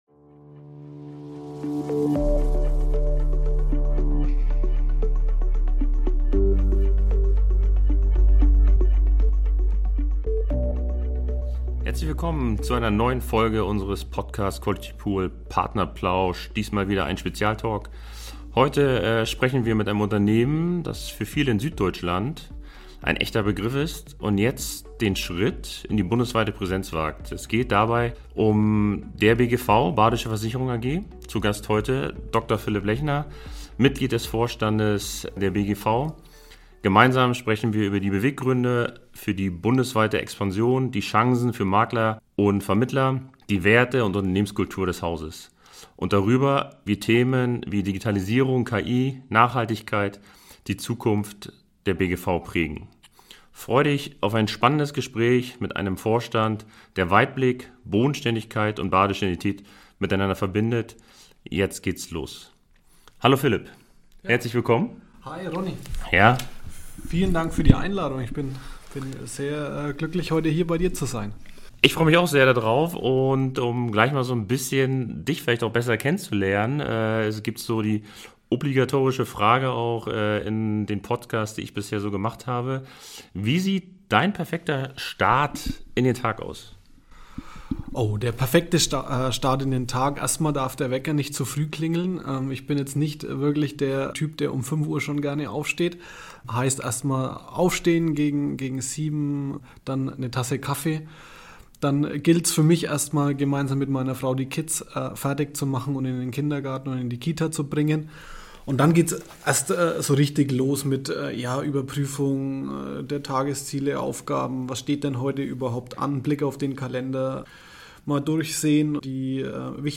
Die beiden unterhalten sich über die Beweggründe für die bundesweite Expansion, die Chancen für Makler:innen und Vermittler:innen, die Werte und Unternehmenskultur des Hauses – und darüber, wie Themen wie Digitalisierung, KI und Nachhaltigkeit die Zukunft der BGV prägen.